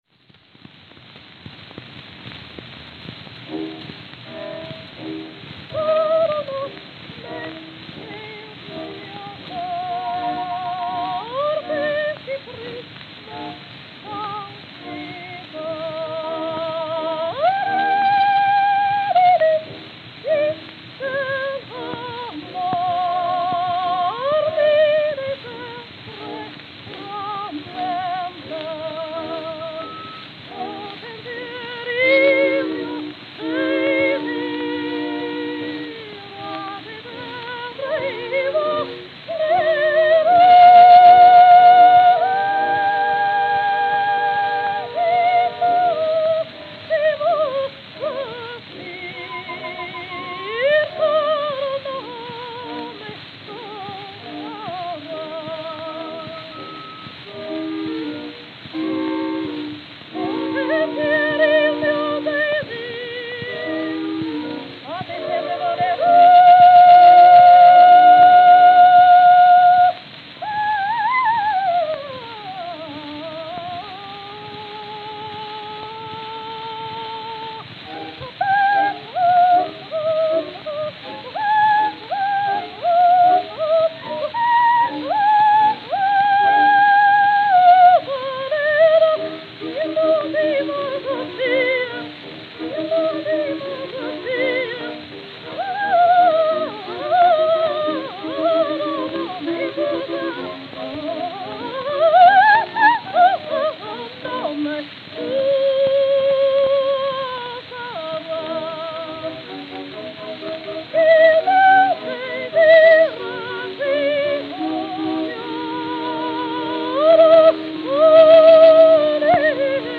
Victor Red Seal 78 RPM Records
Melba
New York, New York